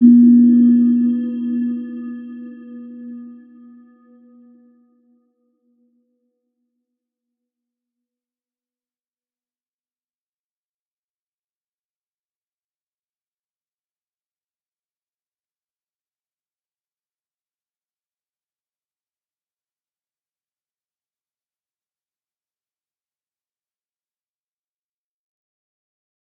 Round-Bell-B3-f.wav